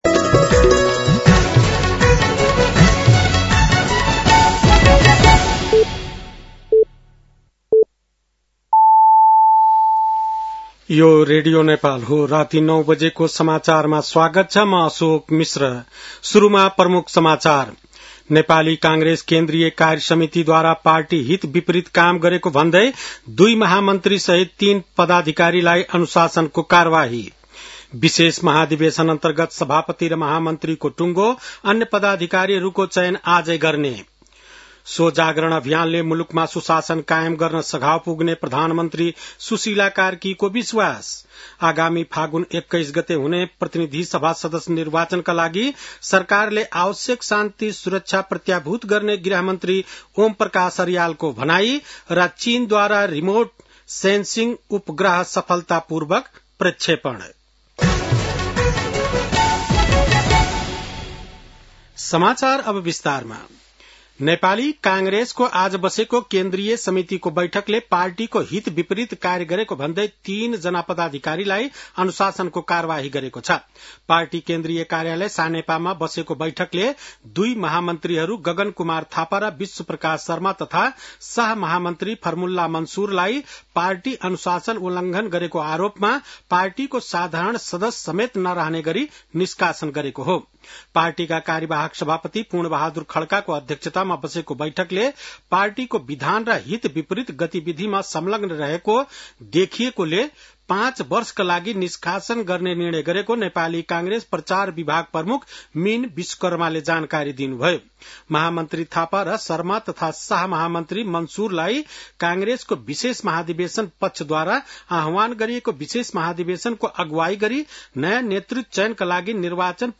बेलुकी ९ बजेको नेपाली समाचार : ३० पुष , २०८२
9-pm-nepali-news-9-30.mp3